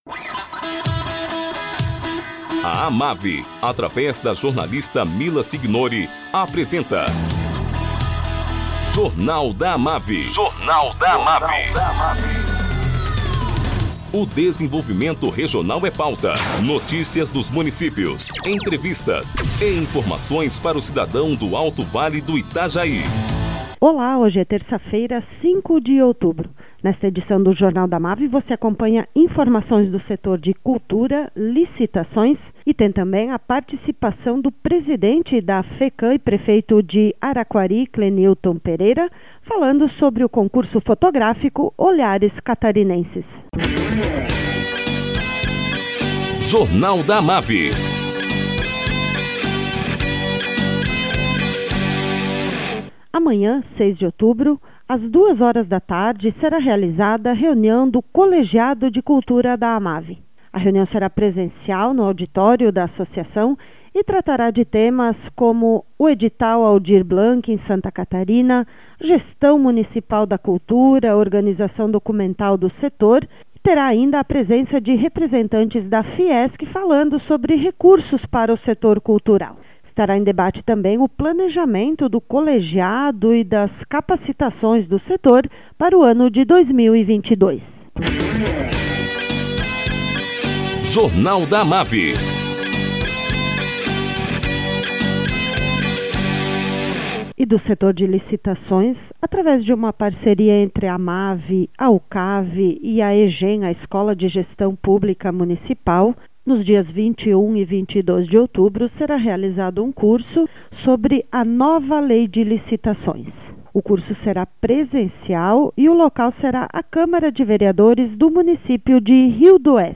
Presidente da FECAM, prefeito de Araquari, Clenilton Pereira, fala sobre o concurso fotográfico "Olhares Catarinenses".